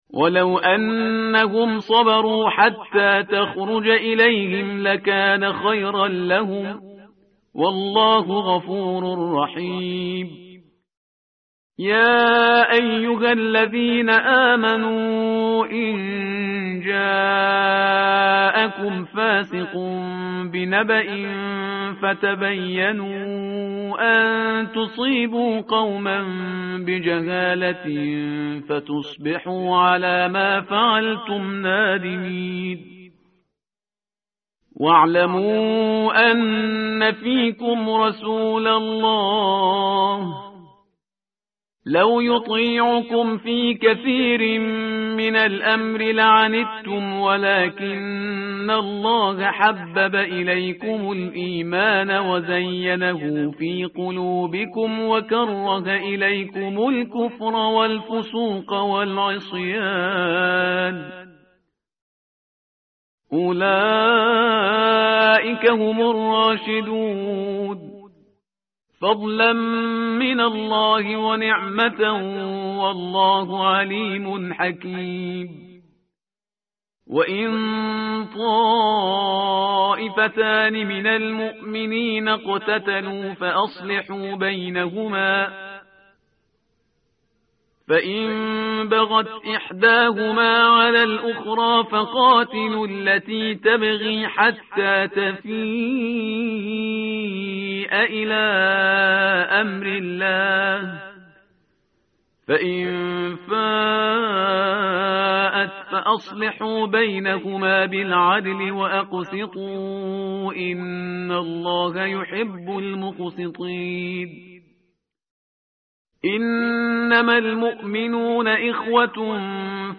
tartil_parhizgar_page_516.mp3